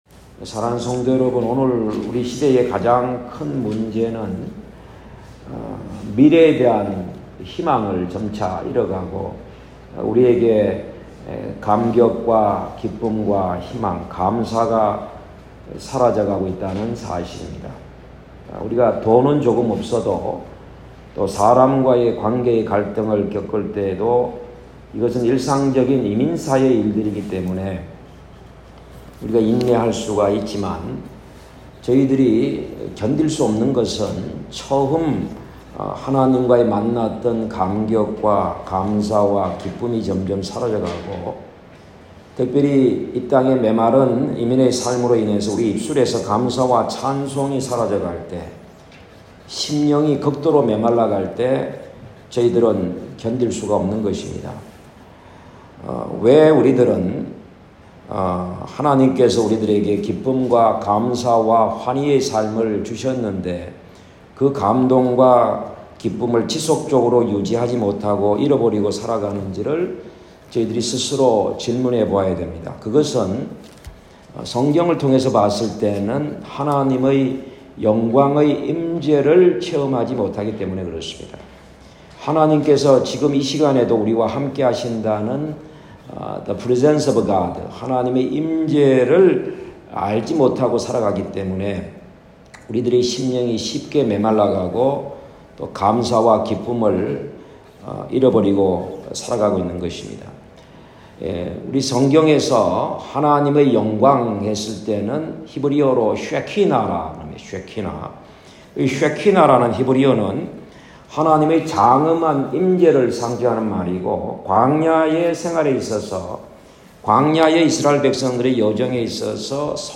2024년 6월 9일 주일 1부예배(오전 9시)설교 Audio